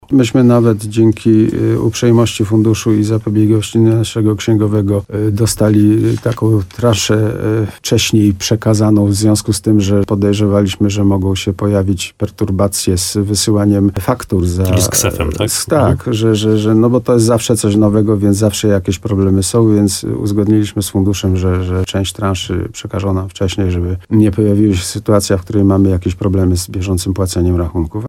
w programie Słowo za Słowo w radiu RDN Nowy Sącz
Rozmowa